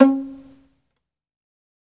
SOLO VIO.4-L.wav